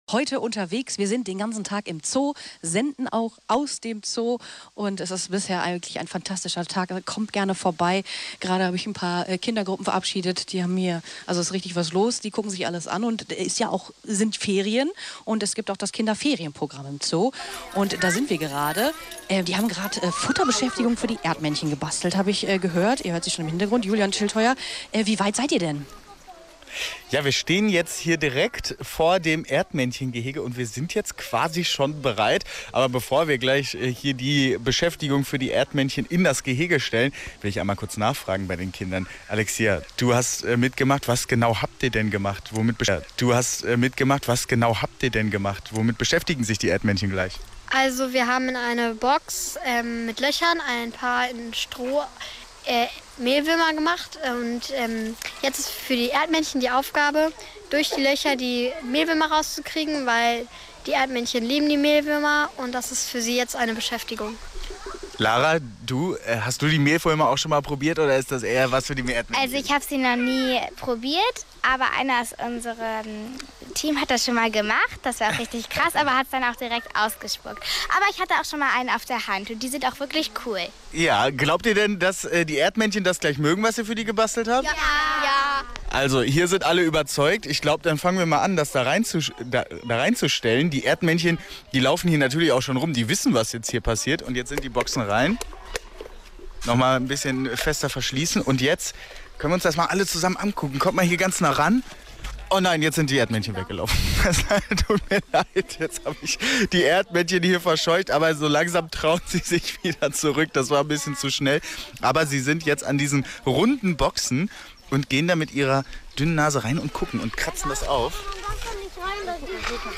Am Freitag (30.06.) haben wir den ganzen Tag aus dem Zoo Dortmund gesendet.